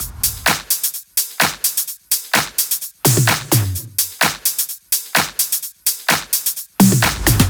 VFH2 128BPM Unimatrix Kit 4.wav